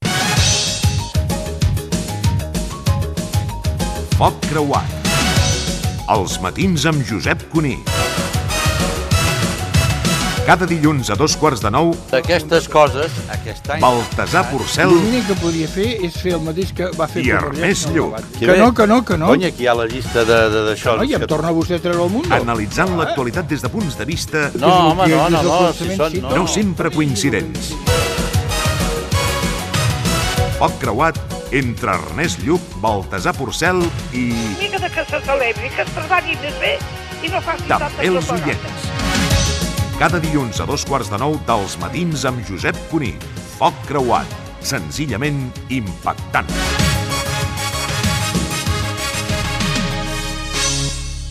Promoció de la secció "Foc creuat" amb Ernest Lluch i Baltasar Porcel.
Info-entreteniment